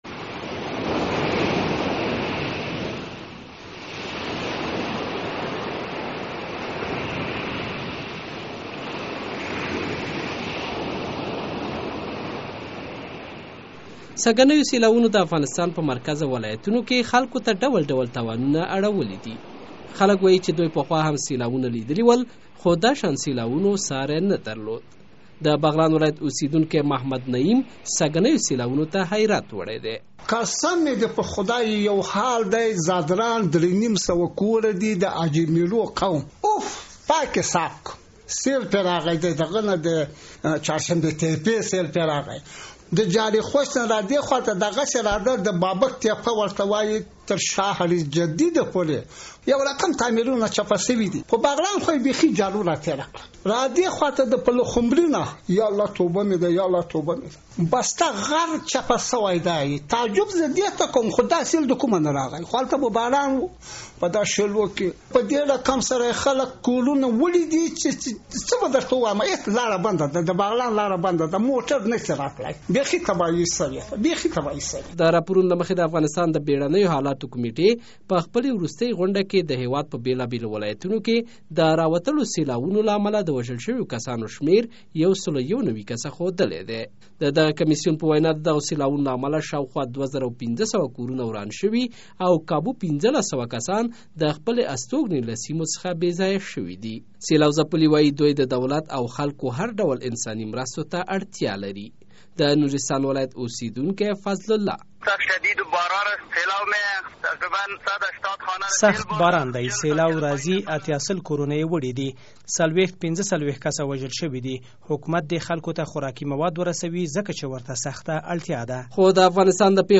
د سیلابونو په اړه ځانګړې راپور